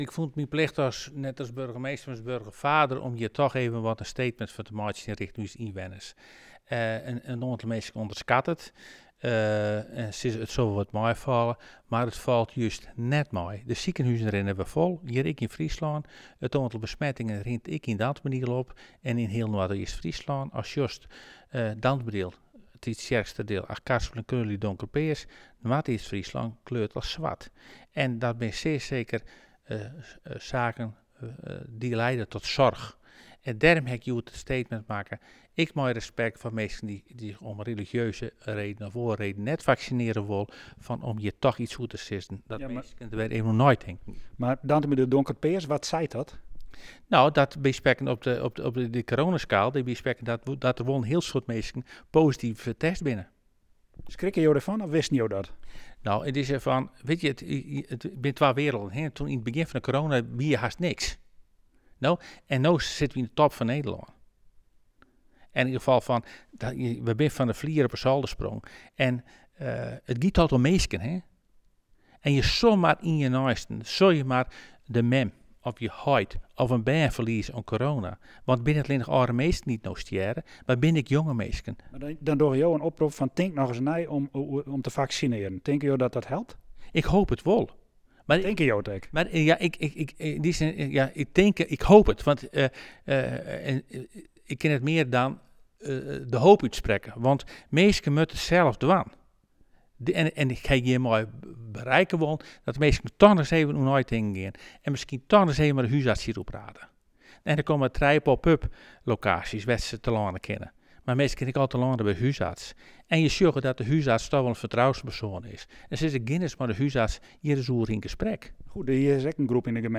Burgemeester Klaas Agricola vertelt waarom hij in de gemeenteraadsvergadering een oproep deed om nog eens na te denken over vaccineren: